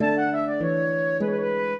flute-harp
minuet6-12.wav